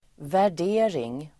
Uttal: [vär_d'e:ring]